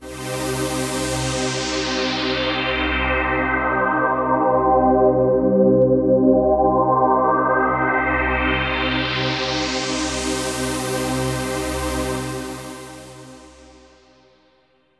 Ambient
SYNTHPAD066_AMBNT_160_C_SC3.wav
1 channel